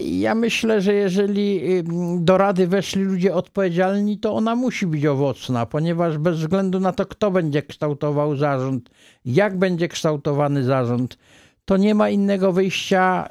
W studiu Radia Rodzina gościliśmy dziś Wiesława Zająca, który podsumował VI kadencję Rady Powiatu Wrocławskiego, opowiedział o perspektywach na kolejną, VII kadencję.